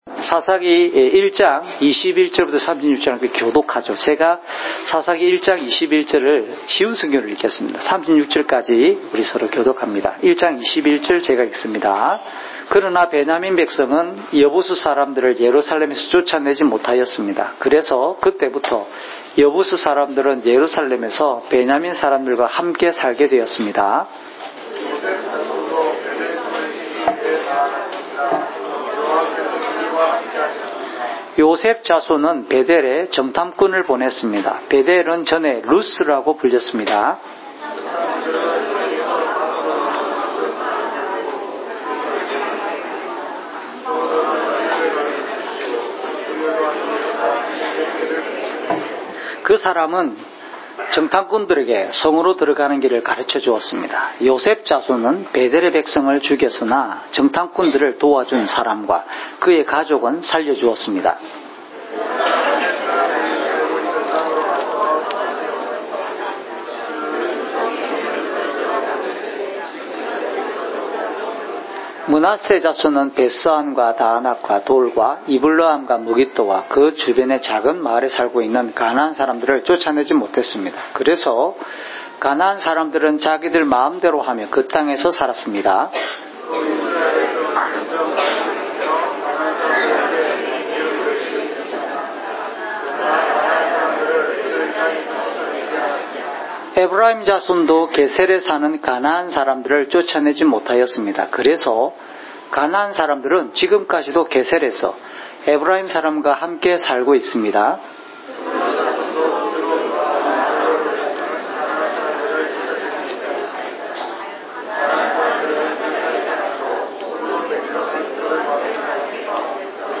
주일설교 - 2019년 2월 24일 “믿음으로 행합시다!"(삿1:21~36)
주일 목사님 설교를 올립니다.